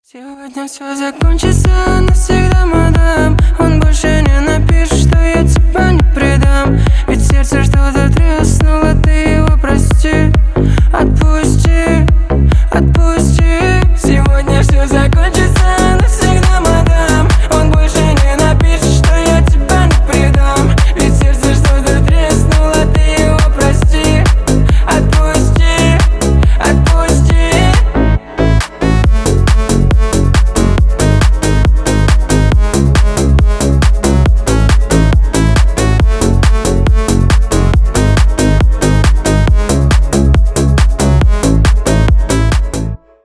русский ритмичный рингтон СКАЧАТЬ РИНГТОН